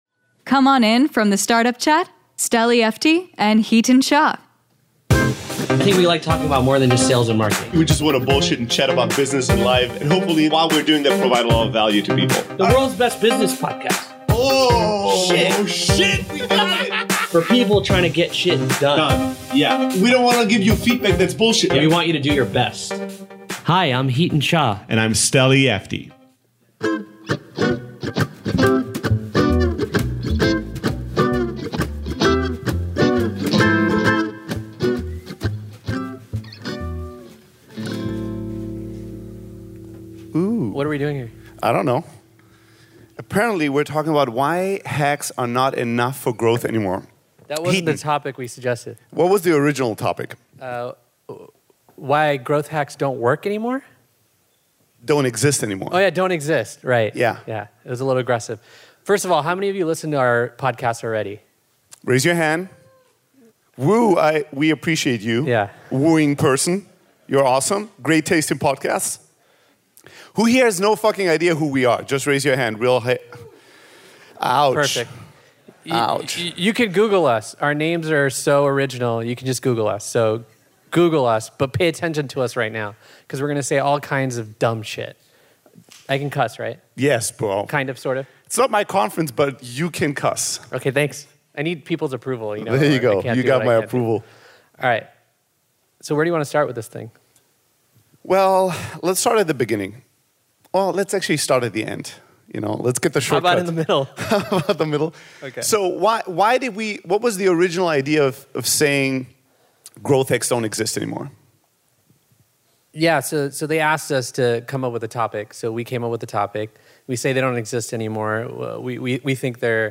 437: Why Hacks Are Not Enough for Growth Anymore (Live from Growthhackers 2019